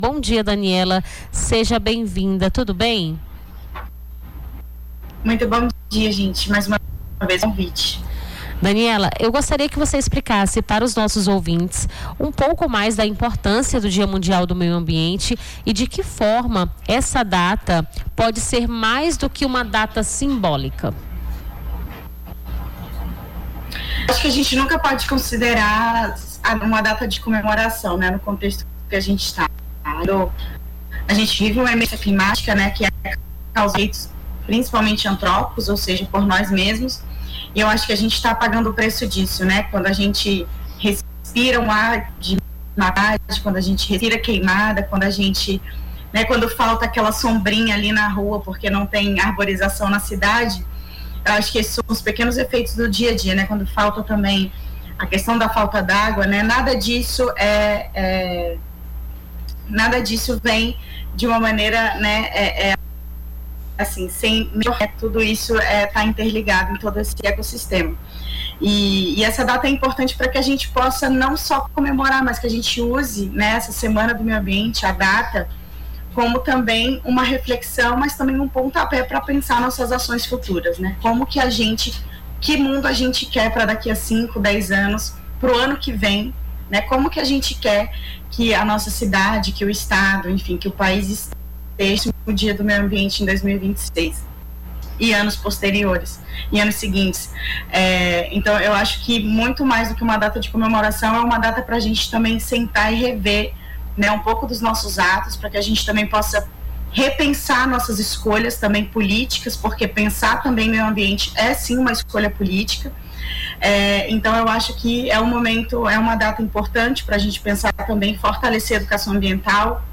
Nome do Artista - CENSURA - ENTREVISTA (DIA MUNDIAL DO MEIO AMBIENTE) 05-06-25.mp3